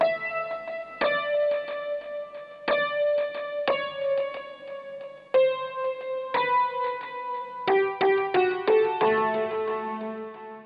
描述：用麦洛特龙钢琴样本做了这个。